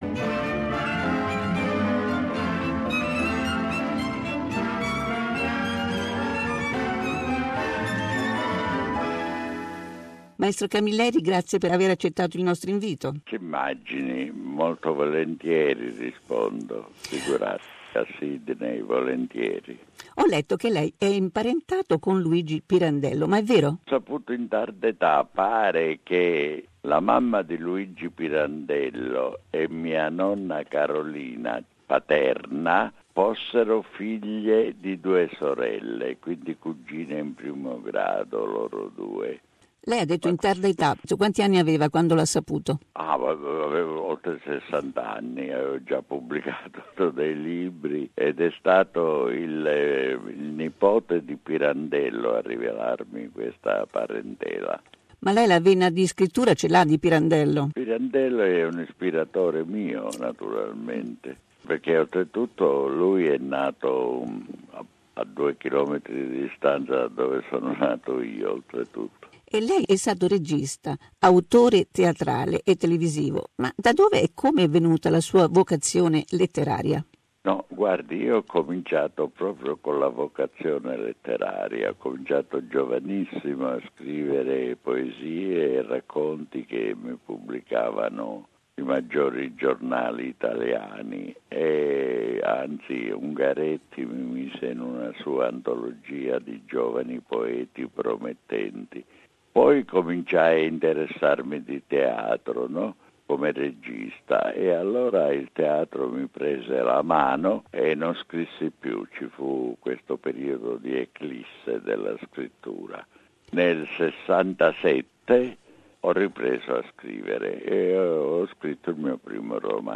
Andrea Camilleri - Intervista esclusiva
Andrea Camilleri è noto soprattutto come l'autore della serie di racconti del Commissario Montalbano, ma la sua carriera di scrittore è iniziata molto prima. Ascoltate la prima parte della nostra intervista.